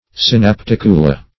Search Result for " synapticula" : The Collaborative International Dictionary of English v.0.48: Synapticula \Syn`ap*tic"u*la\, n.; pl.